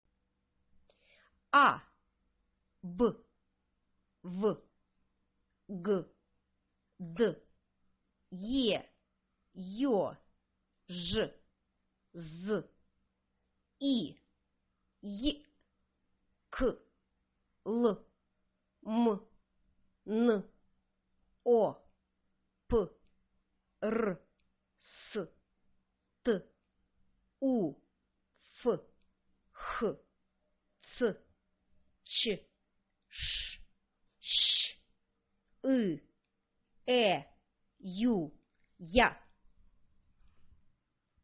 Cyrillic Character and pronunciation. (キリル文字と発音)